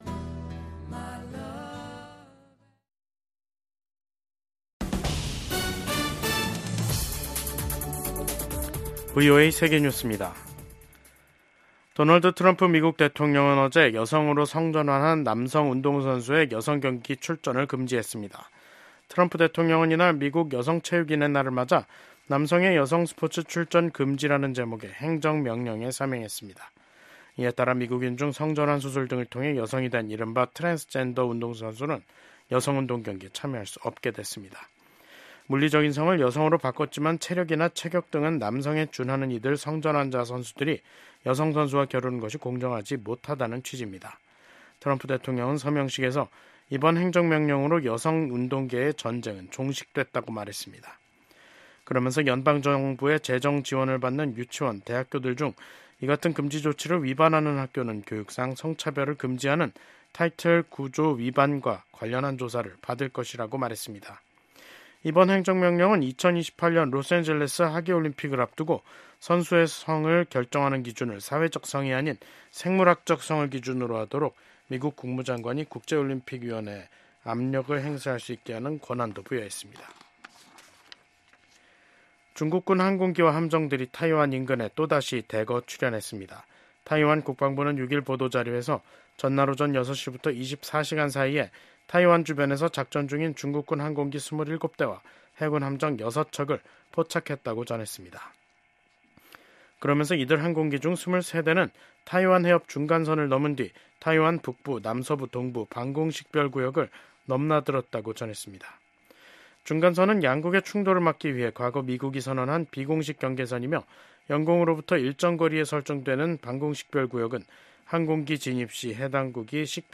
VOA 한국어 간판 뉴스 프로그램 '뉴스 투데이', 2025년 2월 6일 3부 방송입니다. 미국의 도널드 트럼프 행정부 출범으로 냉랭했던 북중 관계에 일정한 변화가 나타날 수 있다는 관측이 제기됩니다. 미국 의회에서 코리아코커스 공동의장을 맡고 있는 의원들이 트럼프 행정부가 들어서면서 한국이 미국과 에너지 협력을 확대하는 데 유리한 환경이 조성됐다는 초당적인 입장을 밝혔습니다.